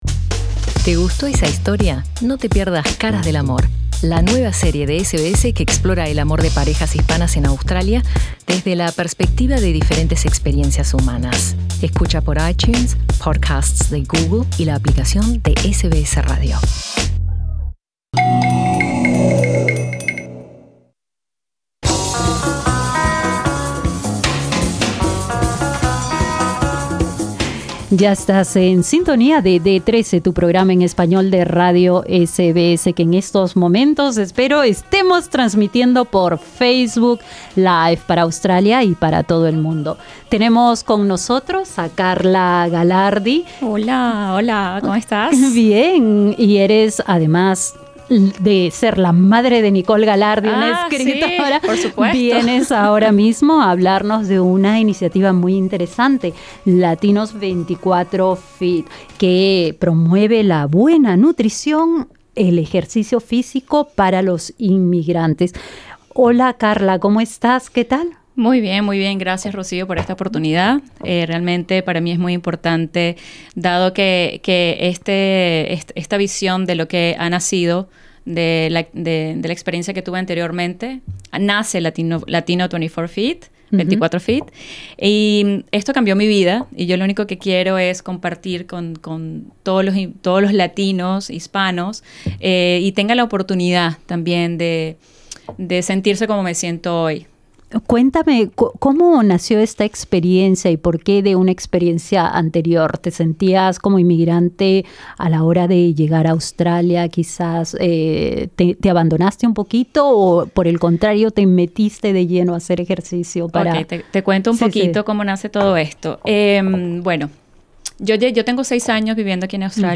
Escucha su experiencia y sus consejos en la entrevista con SBS Spanish.